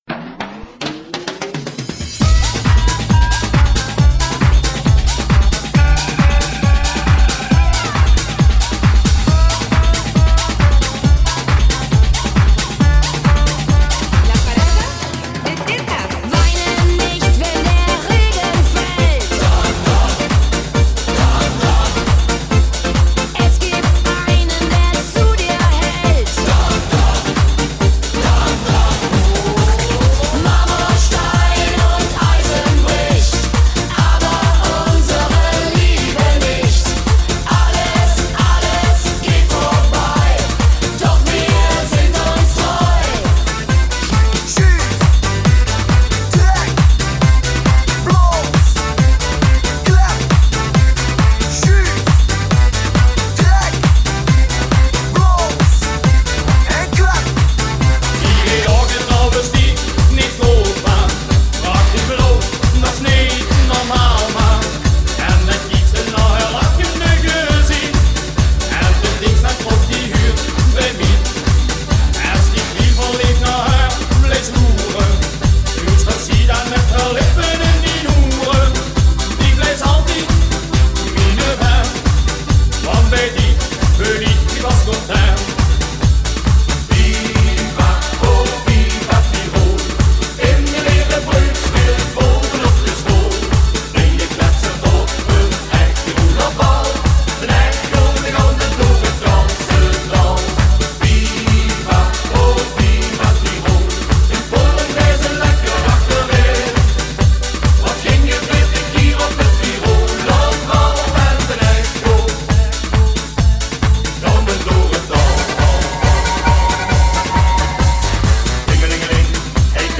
FEBRUARI 2019 • CARNAVAL • 7 MIN